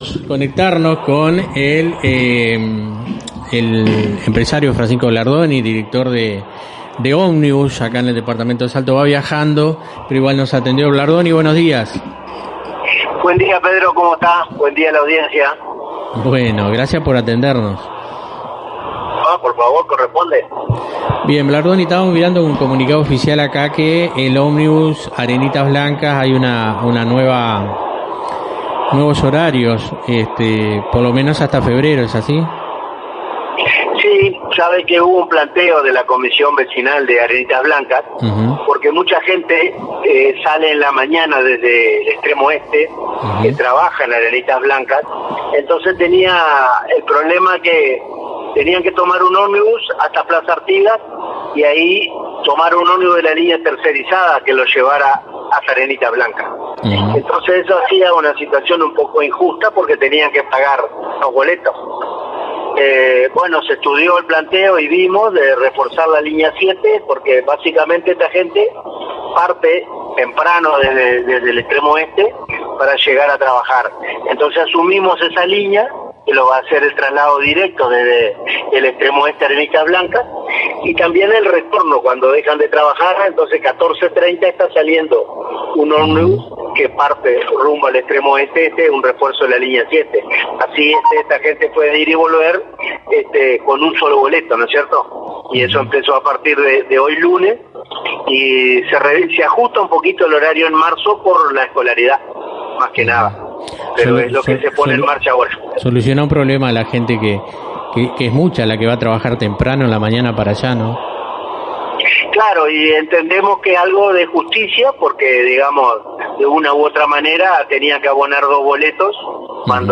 Blardoni atendió la llamada mientras viajaba fuera del departamento, pero igualmente se tomó el tiempo para responder y aclarar varios temas que preocupan a los usuarios.